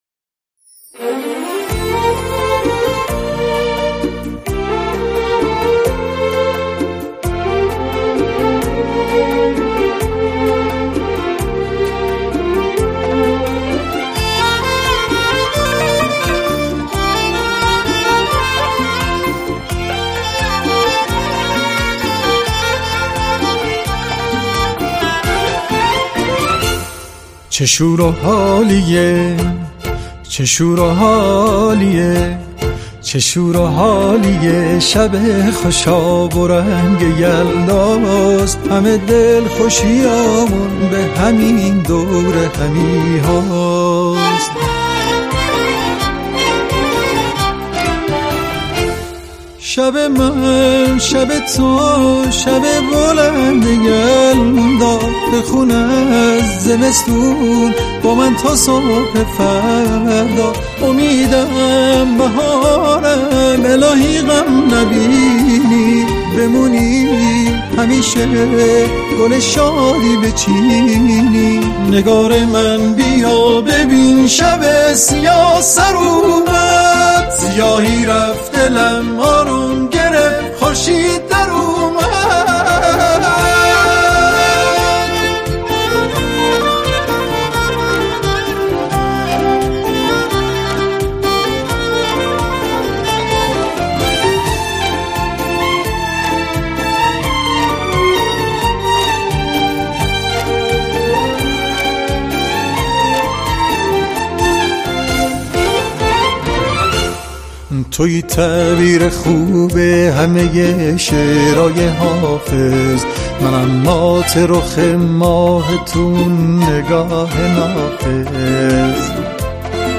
قطعه موسیقی سنتی-تلفیقی
خواننده سنتی‌خوان ایرانی